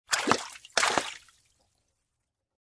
Descarga de Sonidos mp3 Gratis: agua 19.